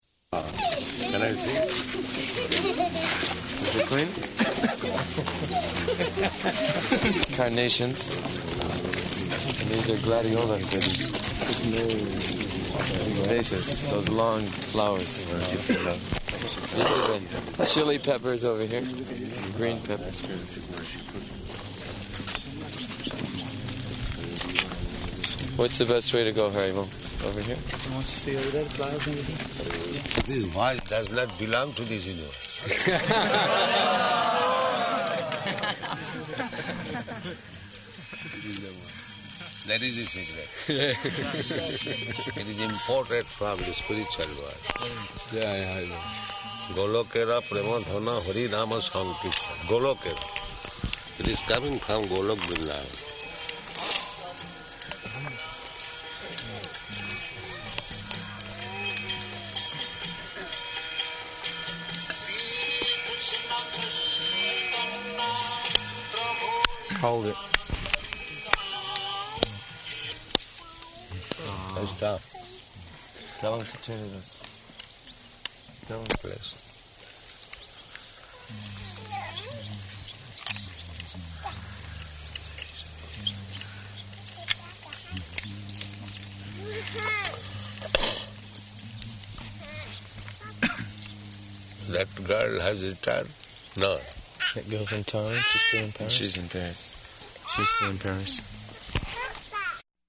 Evening Walk [partially recorded]
Type: Walk
Location: New Māyāpur